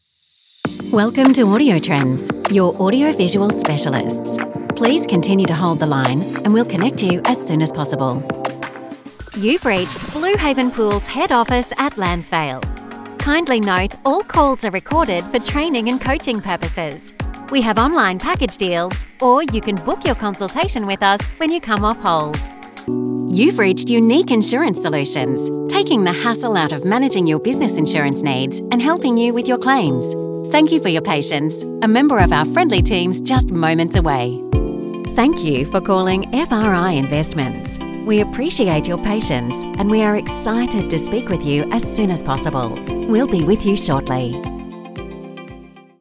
English (Australia)
Commercial, Natural, Versatile, Friendly, Warm
Telephony